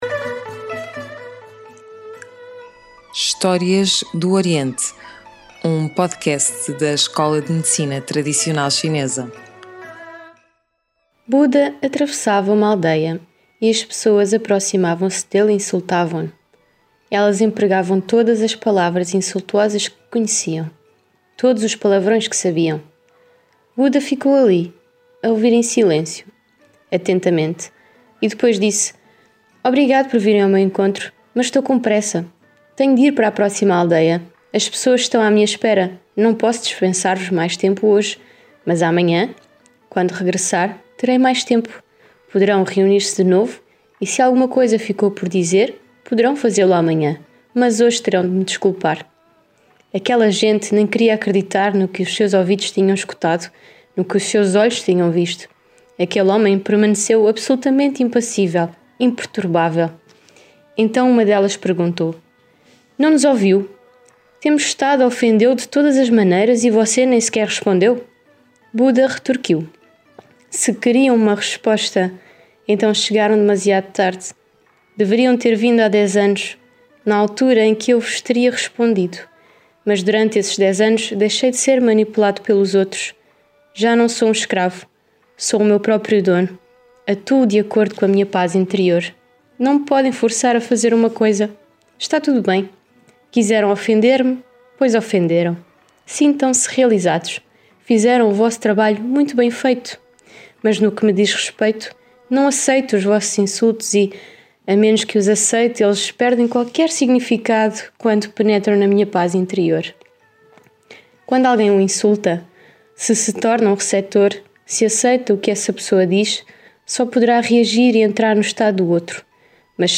Sonoplastia: ESMTC